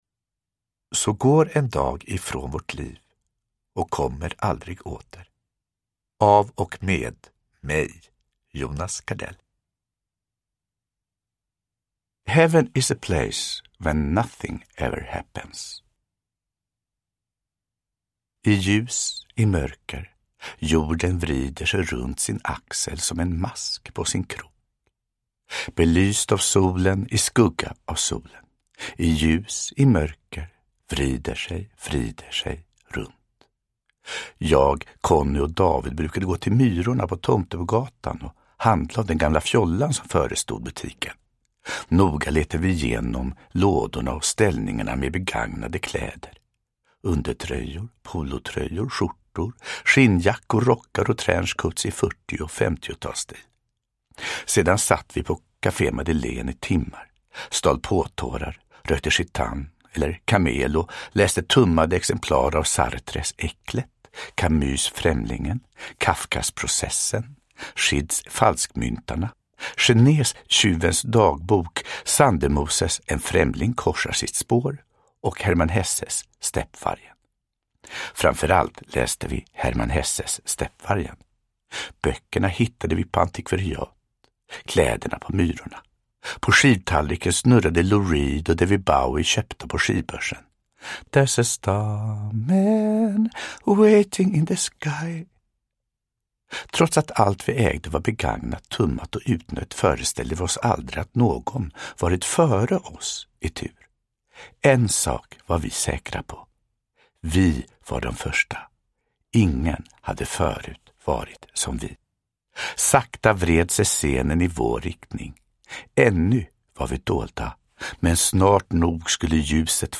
Så går en dag ifrån vårt liv och kommer aldrig åter – Ljudbok – Laddas ner
Uppläsare: Jonas Gardell